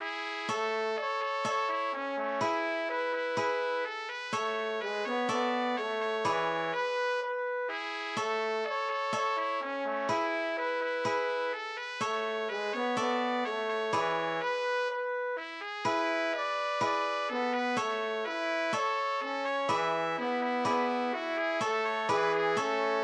Hier geht es zu Melodie und Text